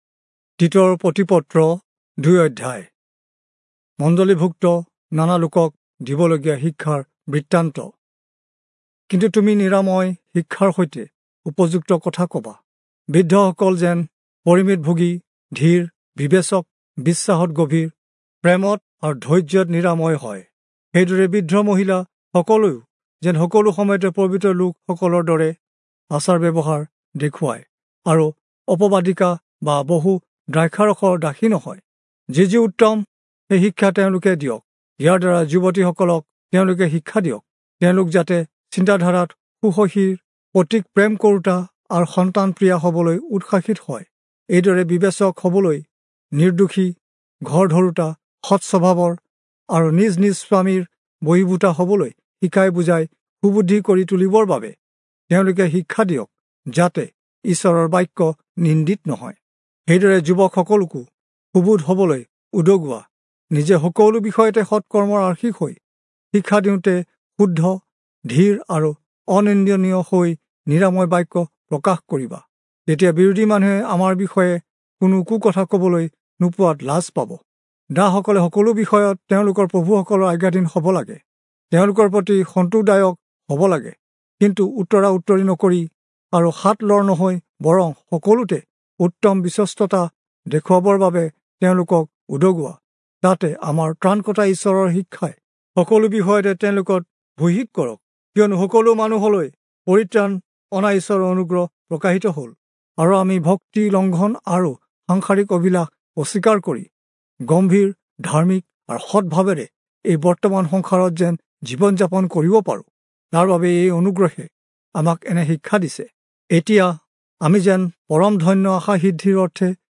Assamese Audio Bible - Titus 2 in Gntwhrp bible version